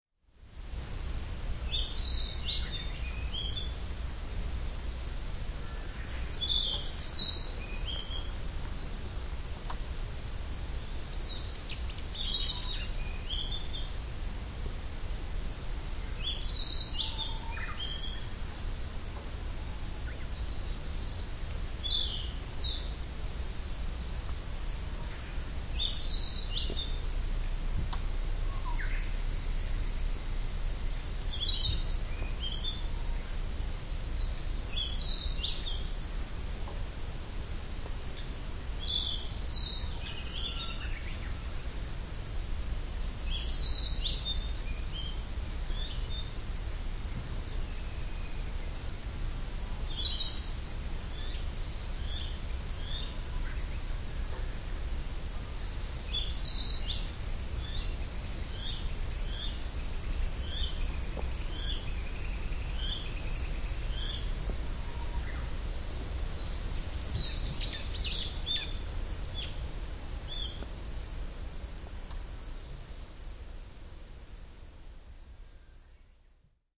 Chirping of Birds.mp3